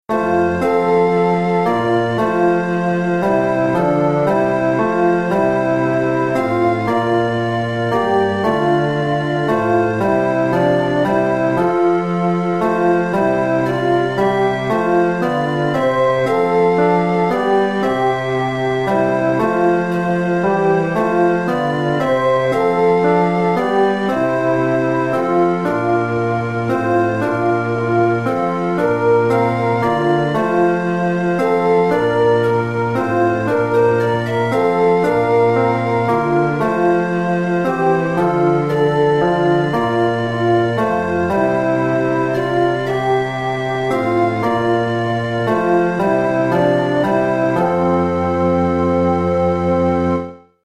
Chorproben MIDI-Files 469 midi files